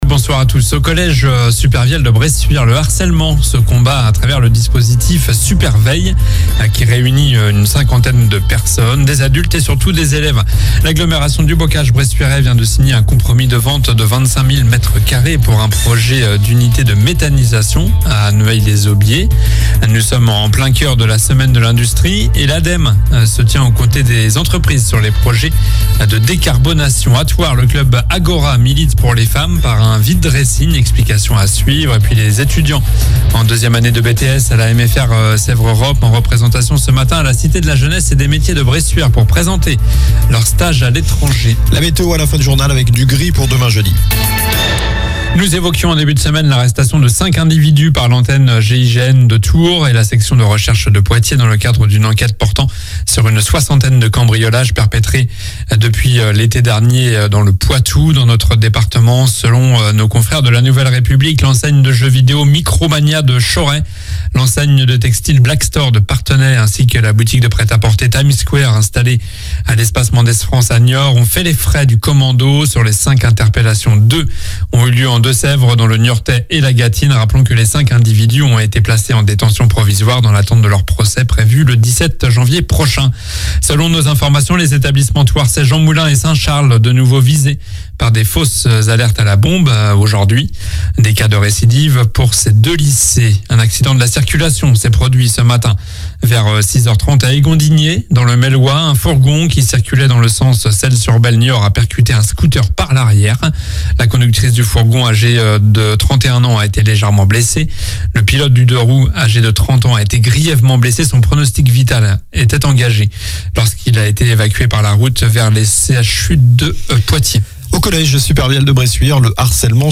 Journal du mercredi 29 novembre (soir)